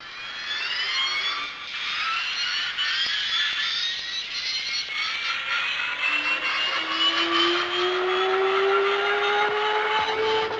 Звук вихря тасманского дьявола из мультфильма